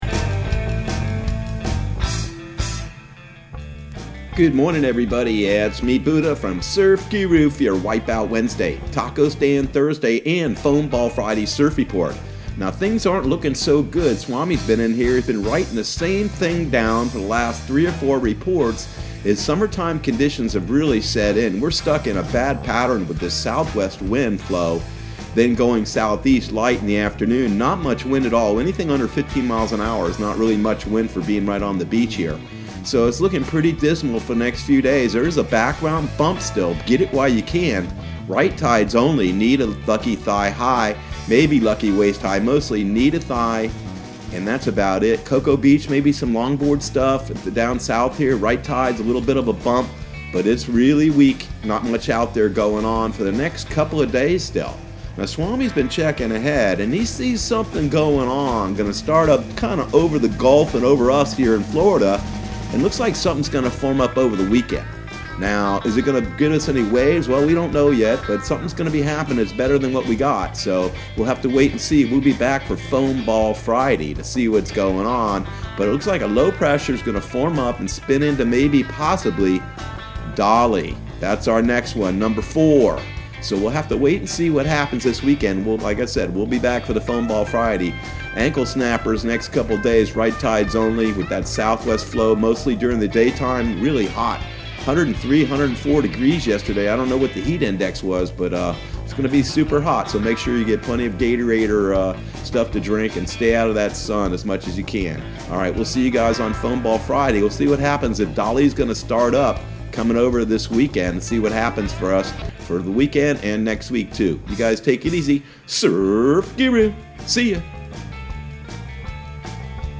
Surf Guru Surf Report and Forecast 07/01/2020 Audio surf report and surf forecast on July 01 for Central Florida and the Southeast.